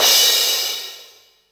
DX Cymbal 01.wav